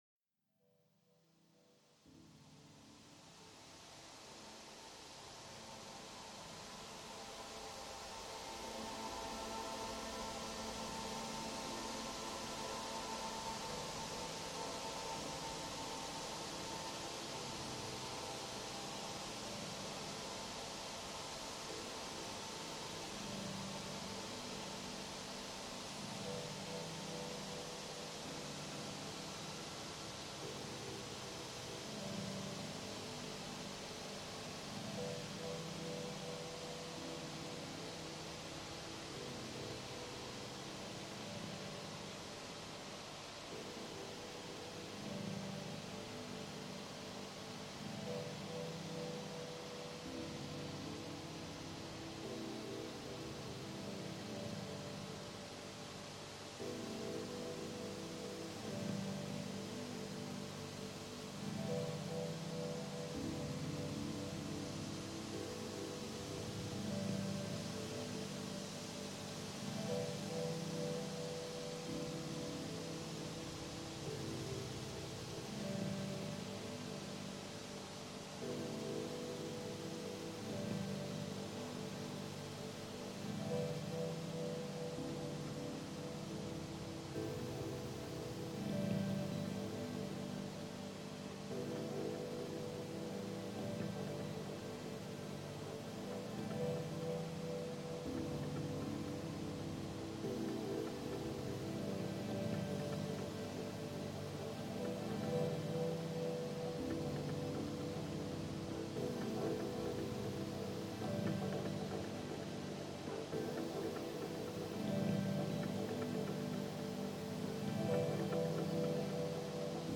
Genre: Ambient/Dub Techno.